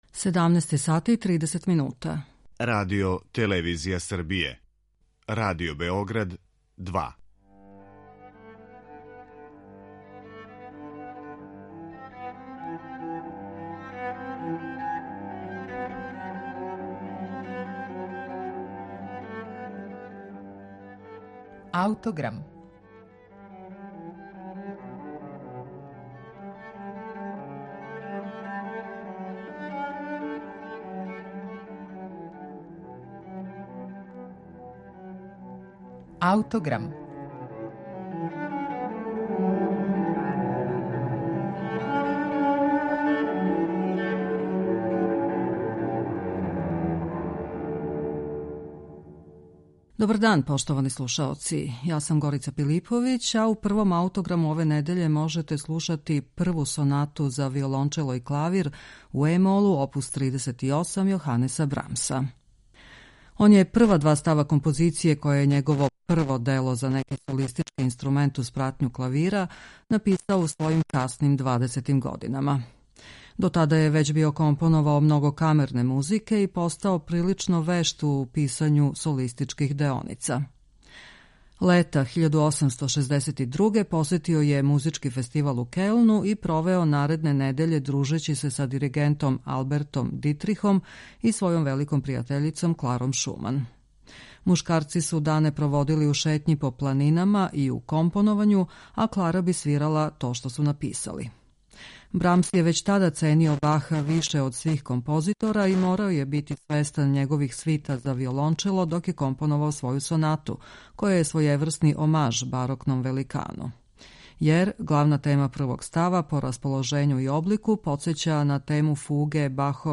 Прва соната за виолончело и клавир
Слушаћемо интерпретацију виолончелисте Анрија Демаркета и пијанисте Мишела Далбертоа.